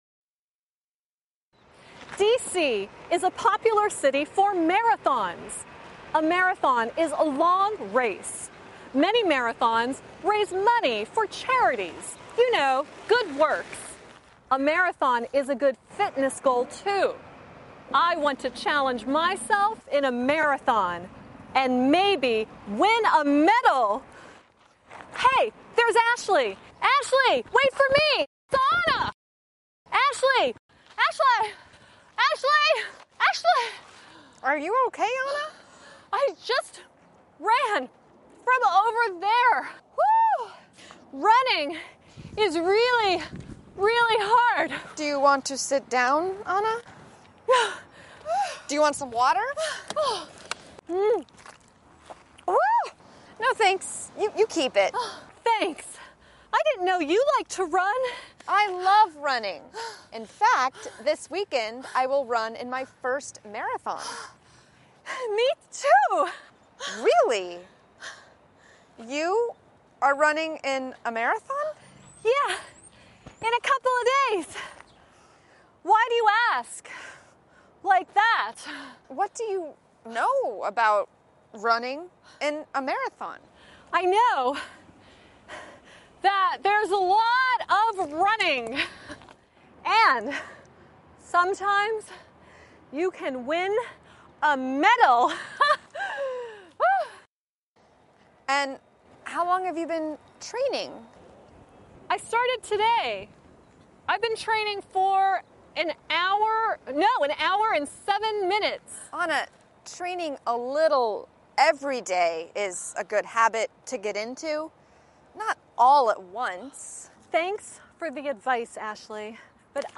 Phần đối thoại (Conversation)